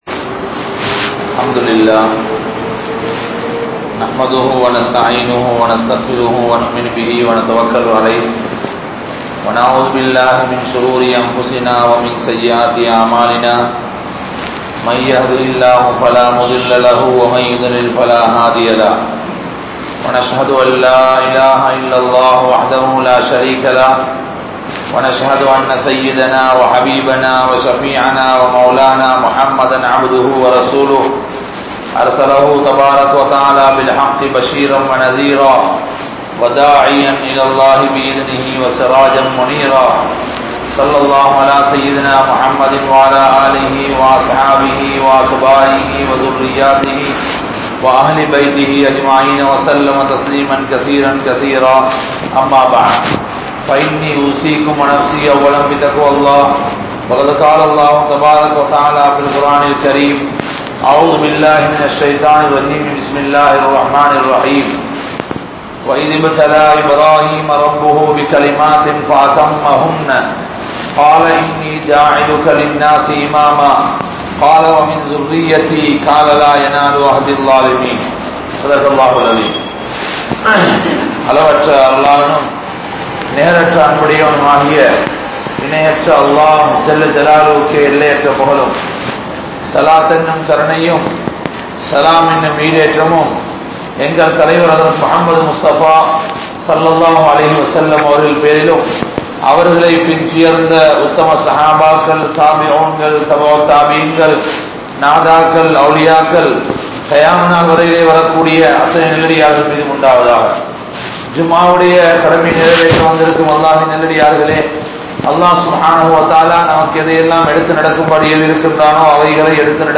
Ibrahim(Alai)Avarhalin Thiyaaham (இப்றாஹீம்(அலை)அவர்களின் தியாகம்) | Audio Bayans | All Ceylon Muslim Youth Community | Addalaichenai